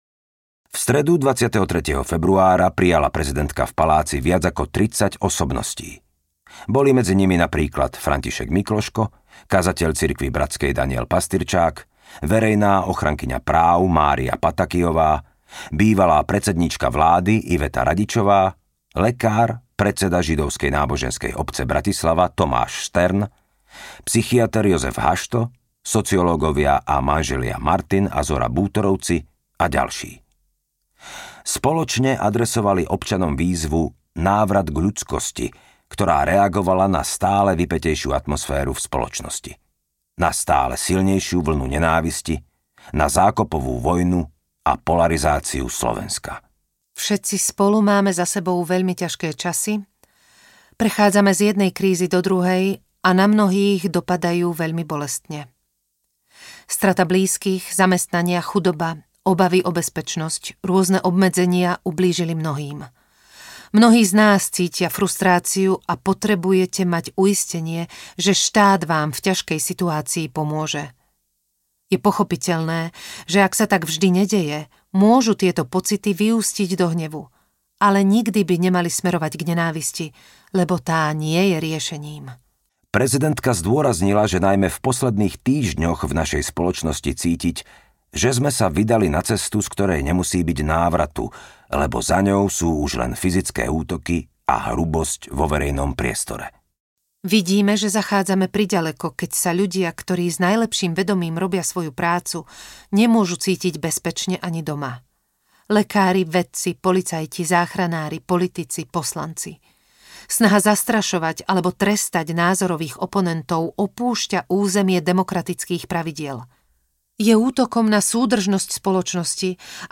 ZUZANA ČAPUTOVÁ Odvaha k ľudskosti audiokniha
Ukázka z knihy
Prvá slovenská prezidentka. Vypočujete si mnohé jej nezabudnuteľné príhovory, vyhlásenia, myšlienky.